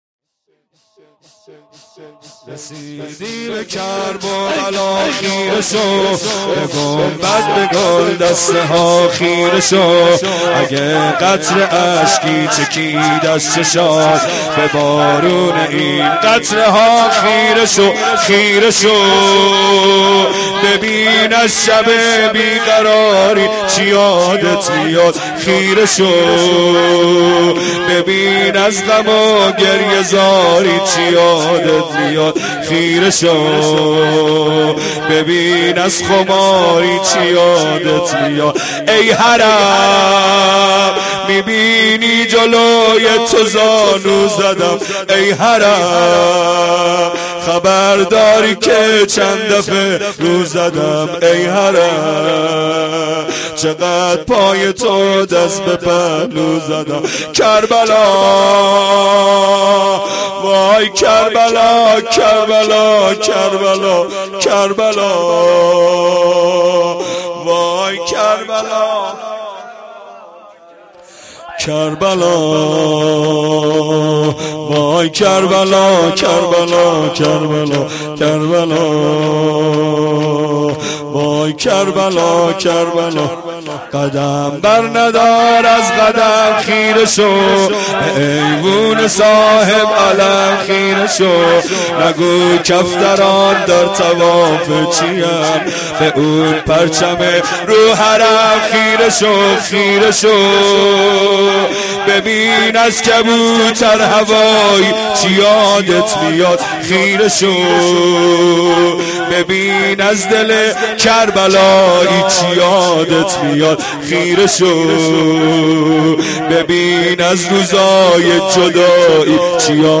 شور2
shoor2-Rozatol-abbas.Esteghbal-az-Ramezan.mp3